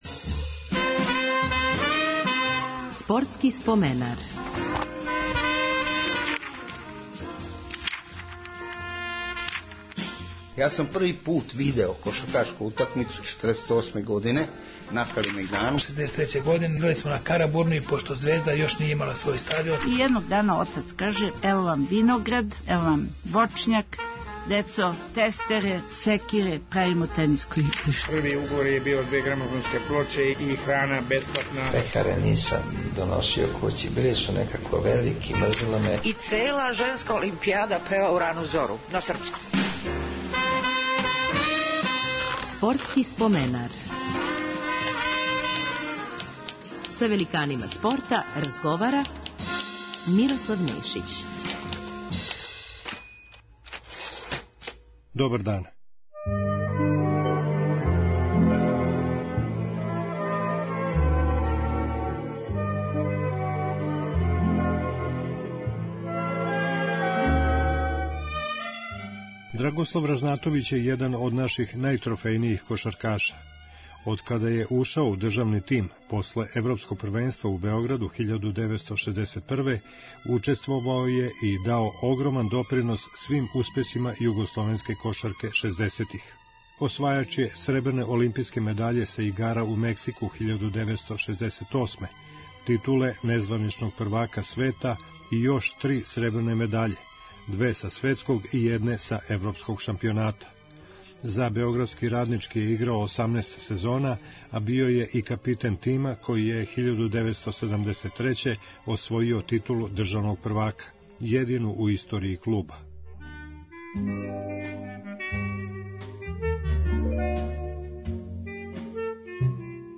Гост 298. Спортског споменара је кошаркаш Драгослав Ражнатовић. Током читаве каријере, 18 сезона, играо је за београдски „Раднички“ са којим је 1973. освојио једину титулу државног првака у историји клуба, а затим доспео и до полуфинала Купа европских шампиона.